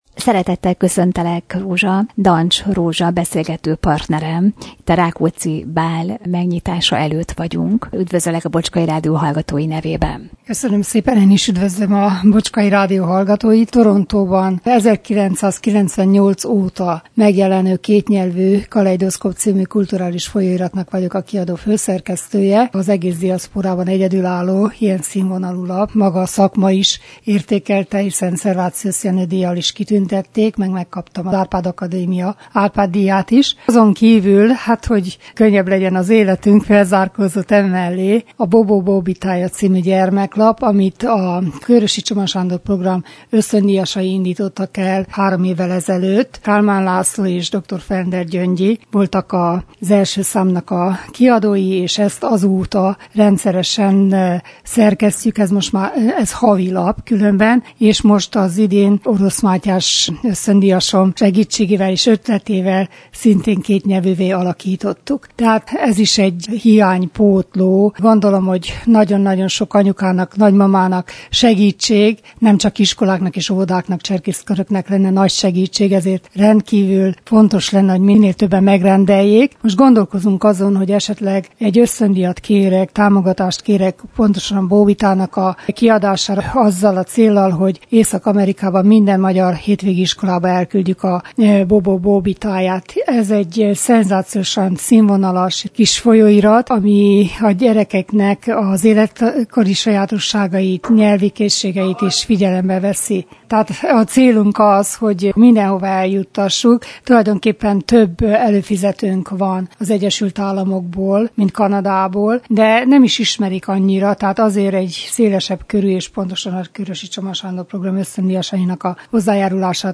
’’Nincs más haza csak az anyanyelv’’- beszélgetés